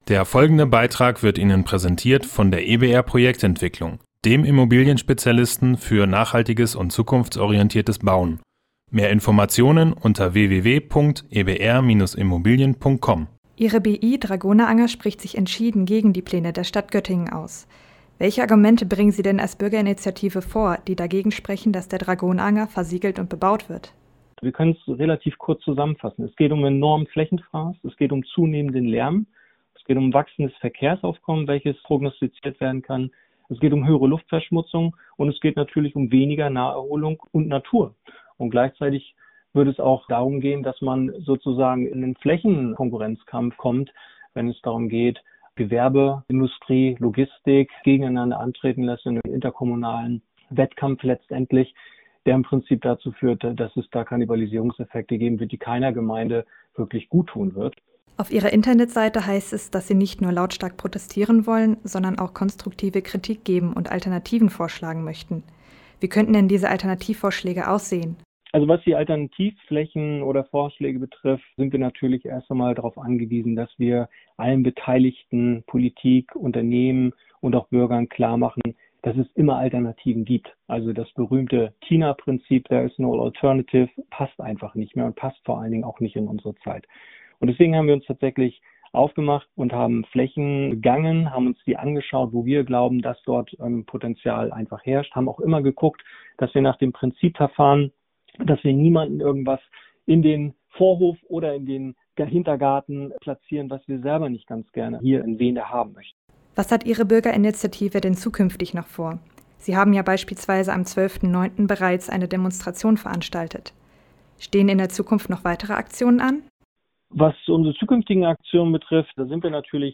Beiträge > Interview mit der BI Dragoneranger- Grüner Ackerboden anstatt grauer Beton - StadtRadio Göttingen